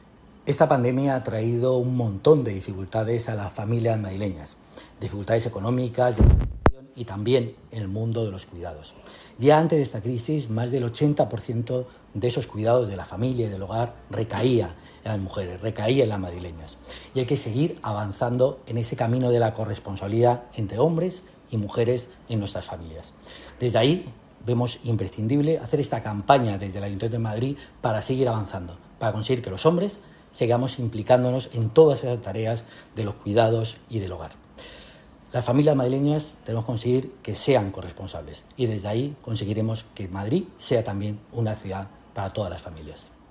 Nueva ventana:Declaraciones de Pepe Aniorte, delegado del Área de Familias, Igualdad y Bienestar Social
Declaraciones Pepe Aniorte.mp3